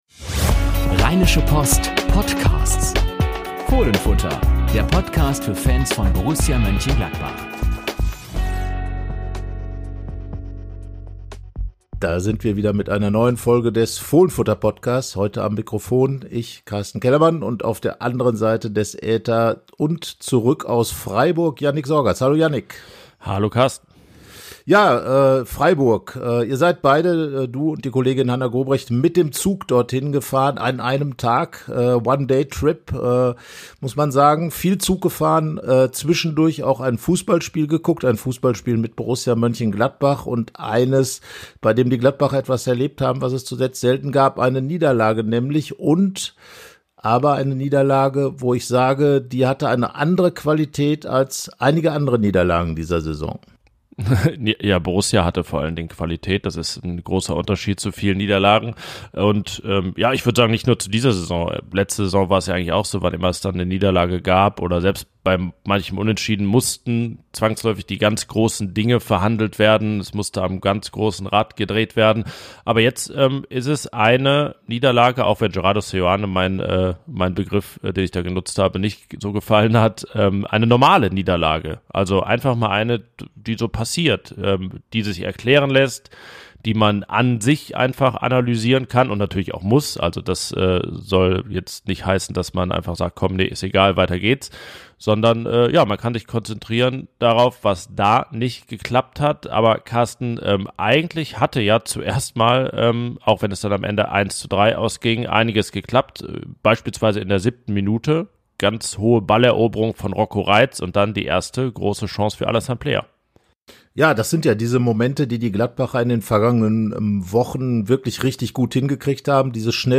Unsere Reporter sprechen über die Situation des Edelkickers und sagen, was dazu geführt hat.